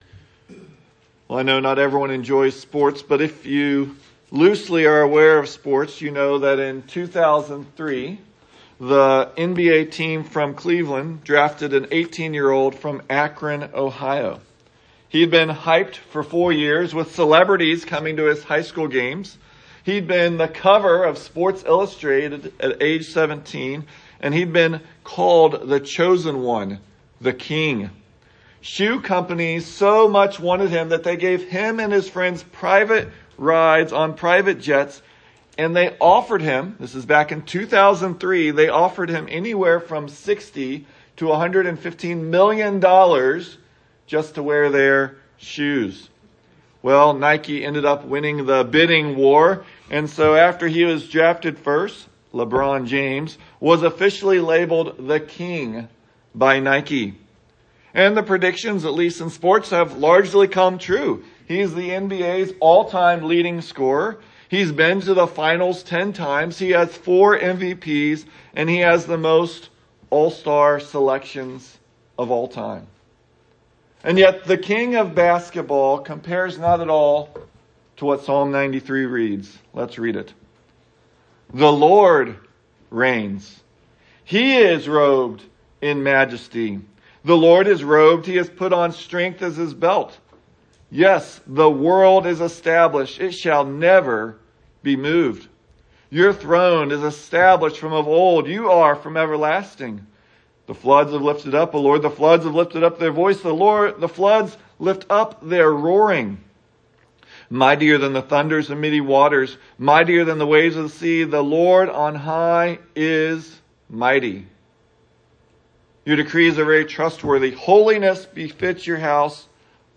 The Unsurpassed Reign of God – Wichita Falls Baptist Church